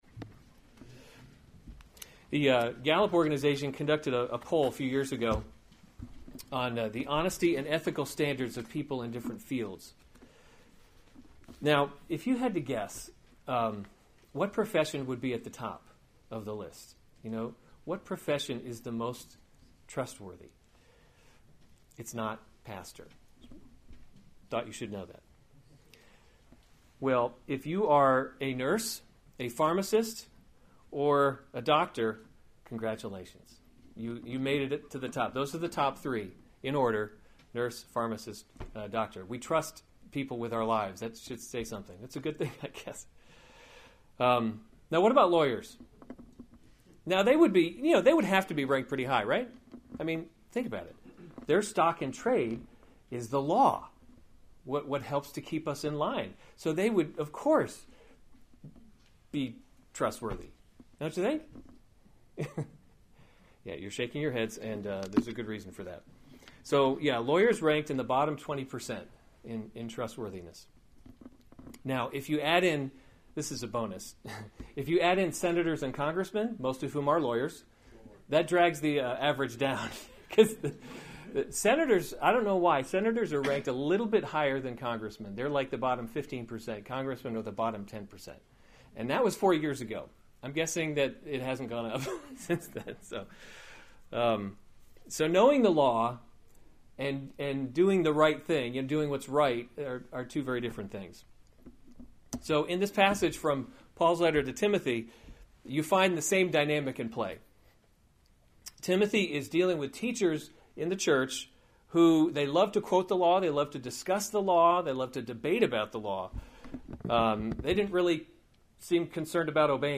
February 11, 2017 1 Timothy – Leading by Example series Weekly Sunday Service Save/Download this sermon 1 Timothy 1:8-11 Other sermons from 1 Timothy 8 Now we know that the law […]